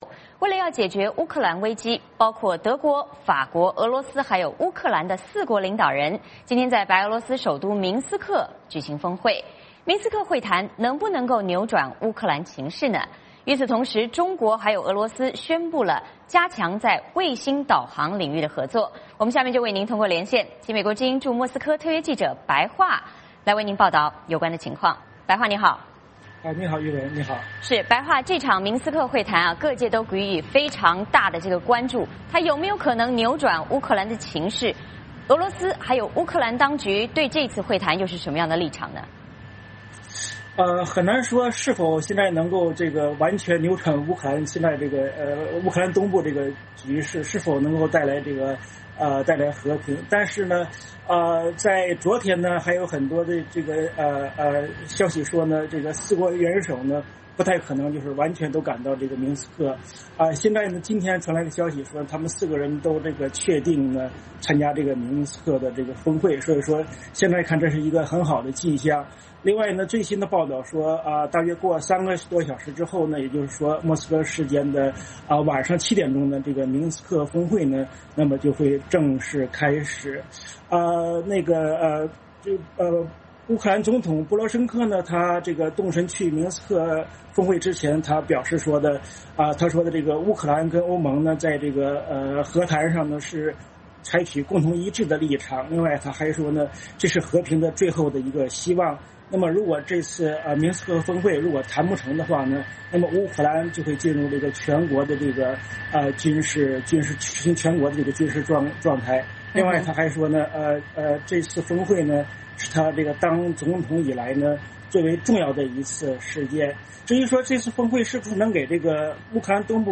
VOA连线：明斯克会谈能否转变乌克兰危机？